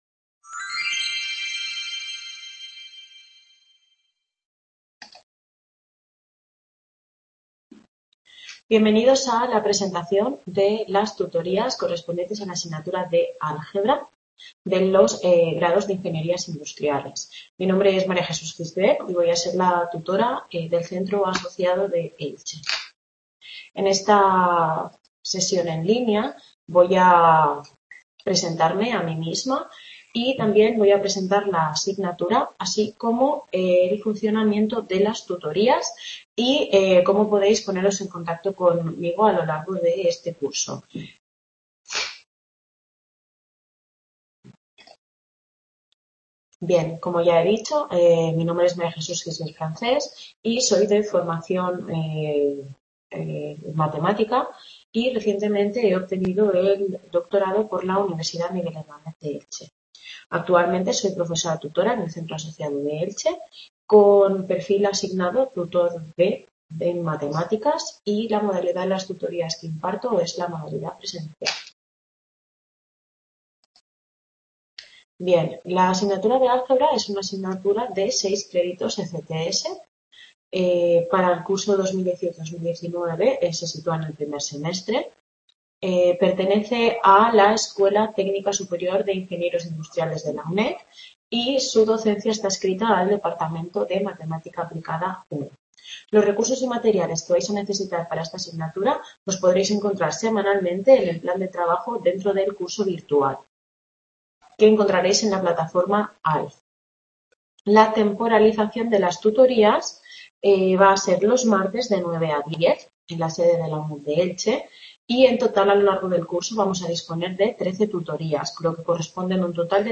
Video Clase
Tutoría (Enseñanza)